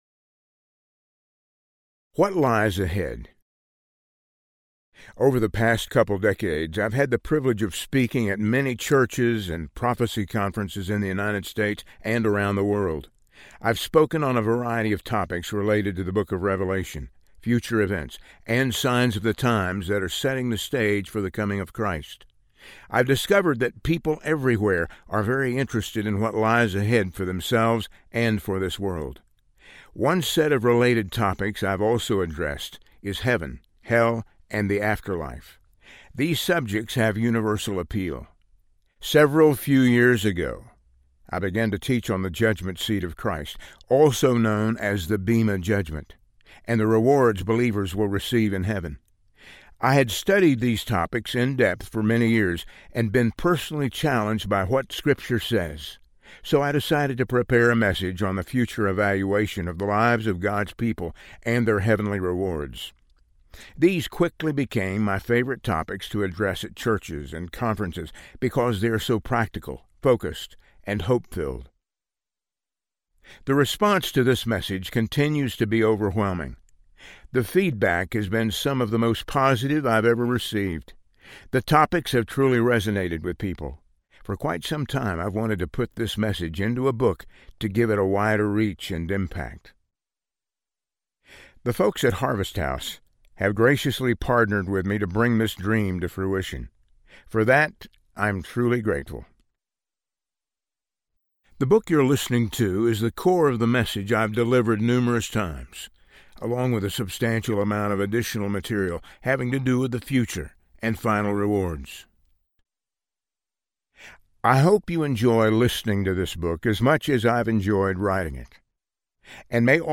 Heavenly Rewards Audiobook
Narrator
5.28 Hrs. – Unabridged